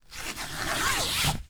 ZIPPER_Long_02_mono.wav